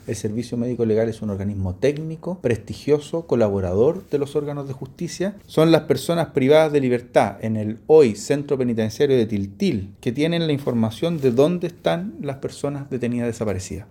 En conversación con La Radio, el ministro de Justicia, Jaime Gajardo, hizo un llamado a no instalar dudas infundadas sobre el trabajo del SML.